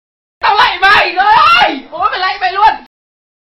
Thể loại: Câu nói Viral Việt Nam
Description: Download sound effect meme Tao lạy mày, Trời ơi, Bố mày lạy mày luôn... của Độ Mixi mp3 edit video....